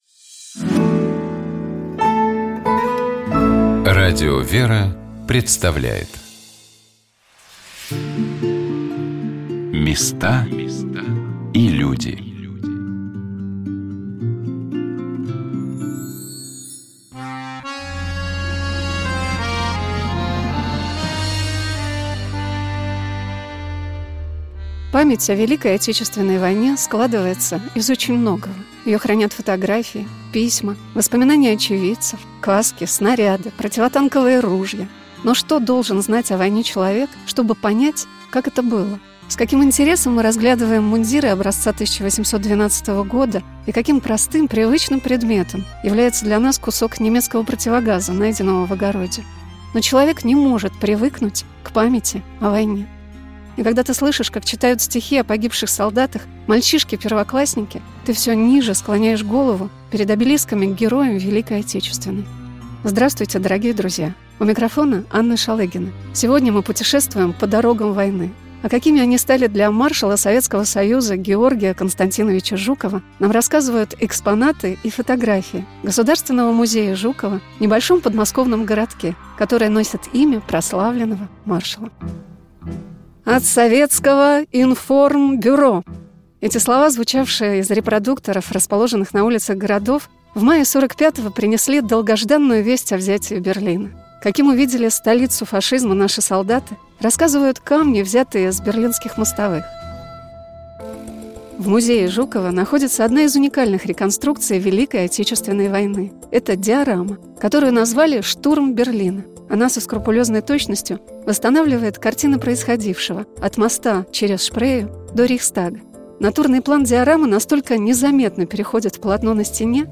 Мы говорили о необычном и сложном пути нашего гостя от рок-музыканта до священника, о промысле Божием в его жизни, и конечно послушали песни в живом исполнении.